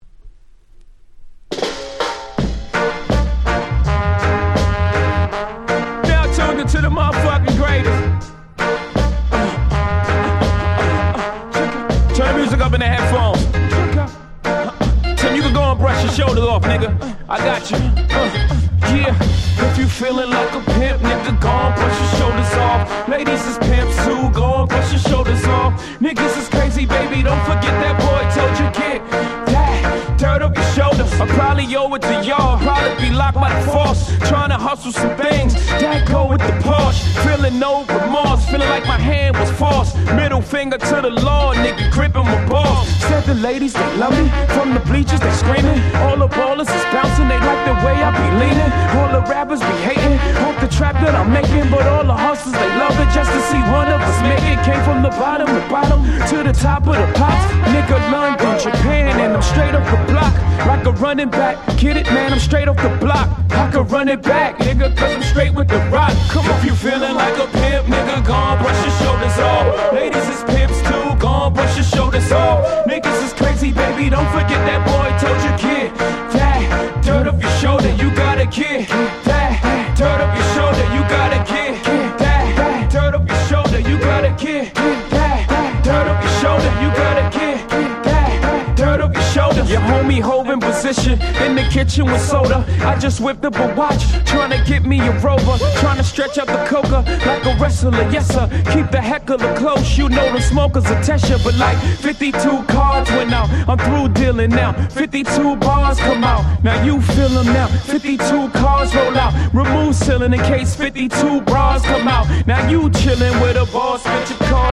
10' Nice Mush Up LP !!